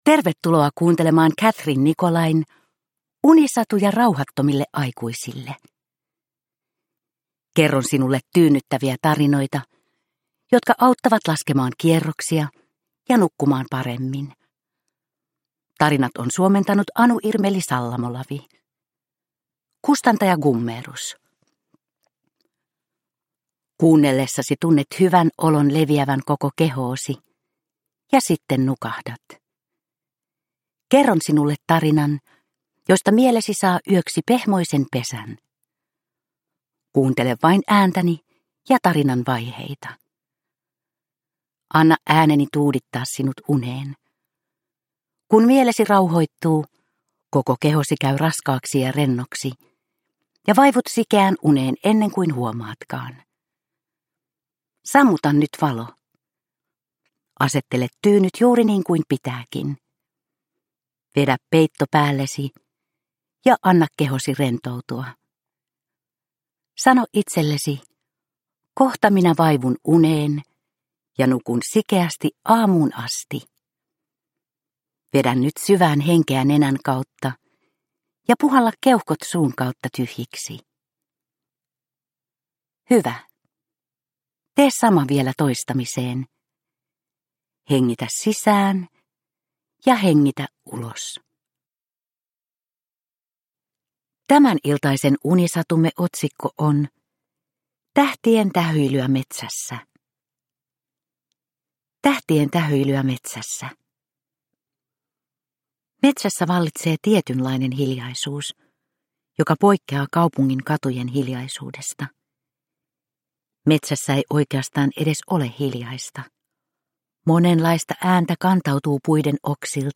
Uppläsare: Vuokko Hovatta